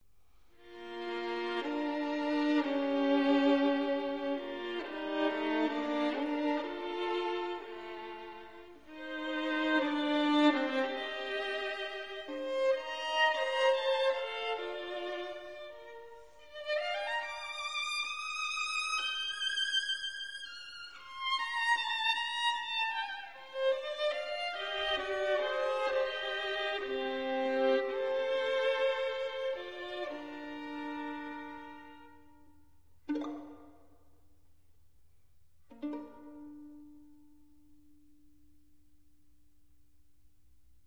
Sonata for Violin Solo in D major Op. 115